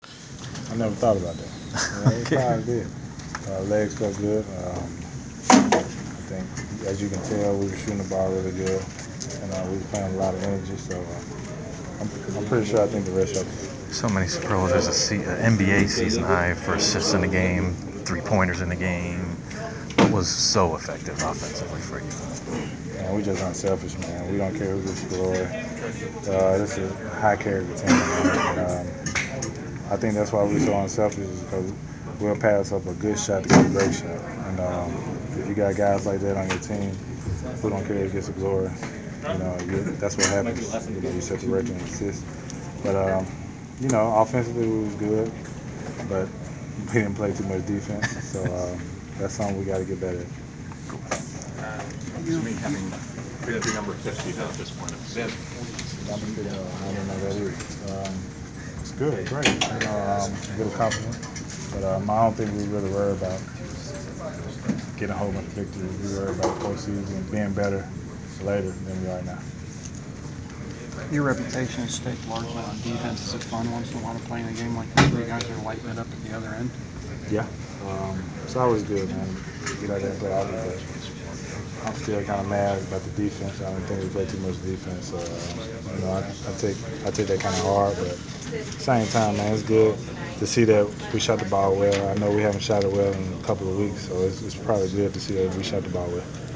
Inside the Inquirer: Postgame presser with Atlanta Hawk DeMarre Carroll (3/9/15)